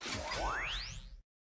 added sound effects
sound_startup.mp3